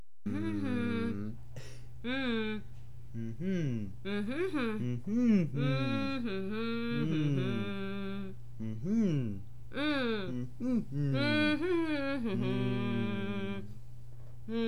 humming.mp3